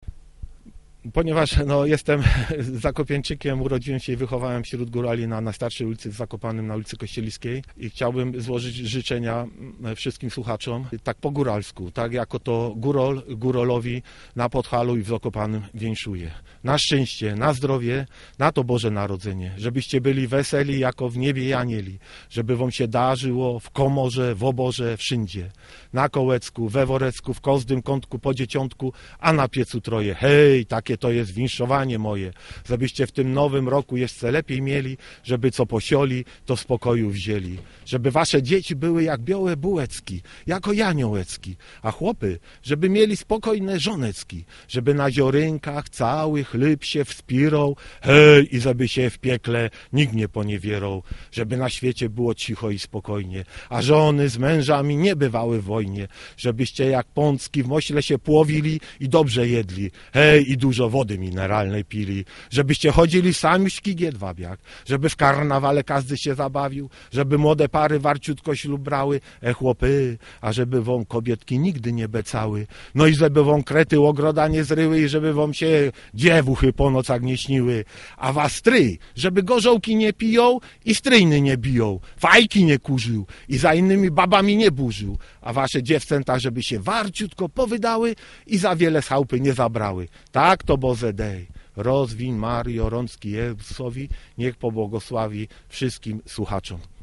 Posłuchajcie więc góralskich życzeń dla wszystkich ceprów.